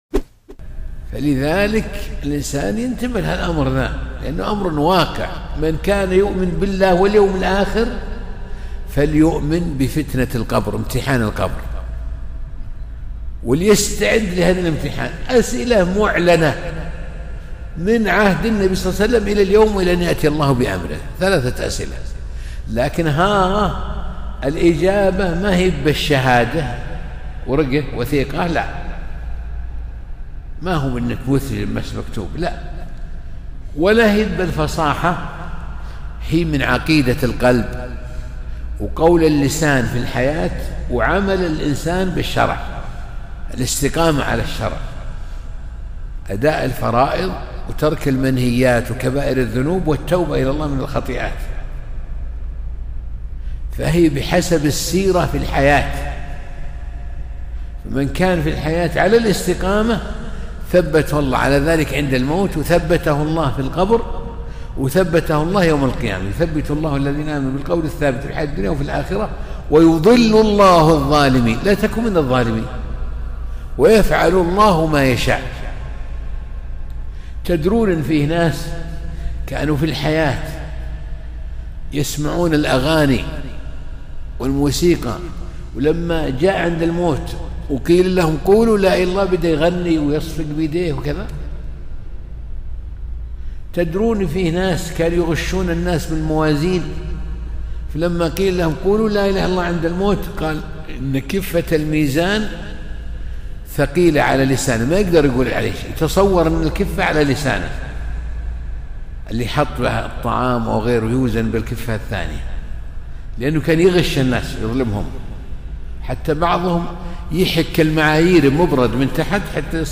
موعظة مؤثرة - فماذا أعددت لما بعد الموت ‼